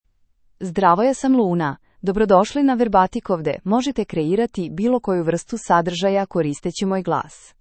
Luna — Female Serbian AI voice
Luna is a female AI voice for Serbian (Serbia).
Voice sample
Listen to Luna's female Serbian voice.
Luna delivers clear pronunciation with authentic Serbia Serbian intonation, making your content sound professionally produced.